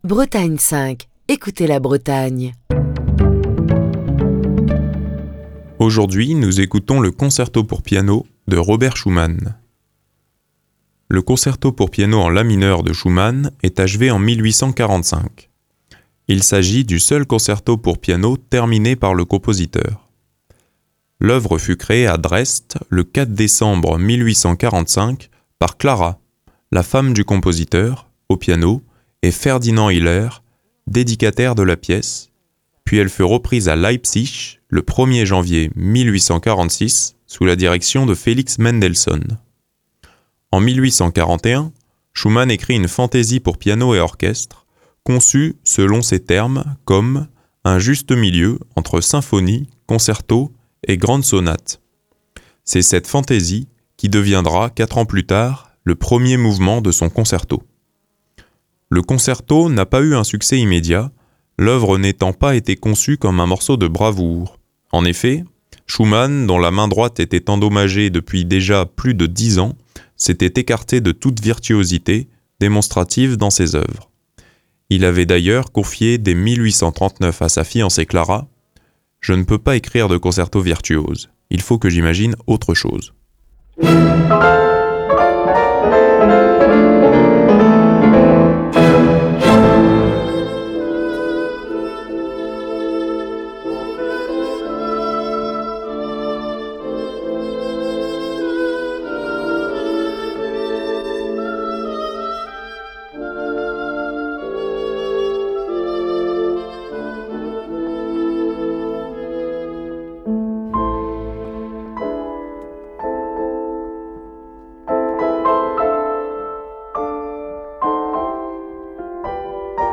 Fil d'Ariane Accueil Les podcasts Le Concerto pour piano N°1 en la mineur de Robert Schumann Le Concerto pour piano N°1 en la mineur de Robert Schumann Émission du 1er avril 2024.